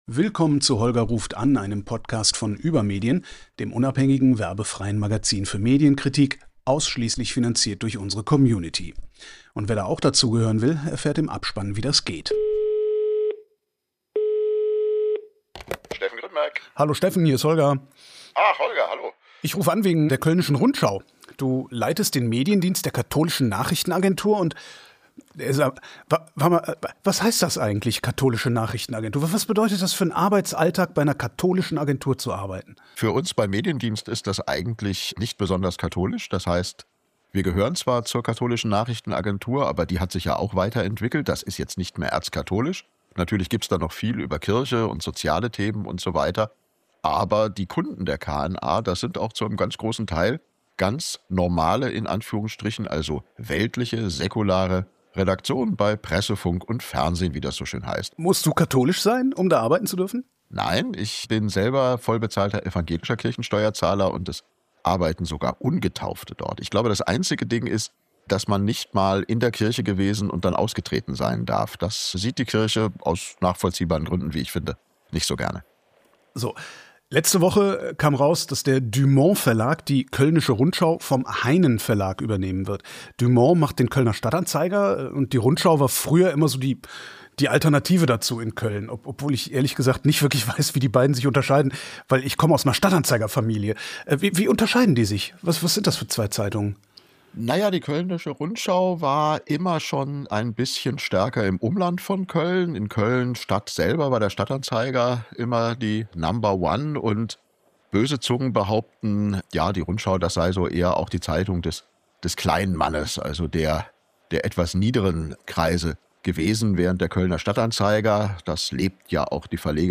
Medienjournalist Zwei Lokalzeitungen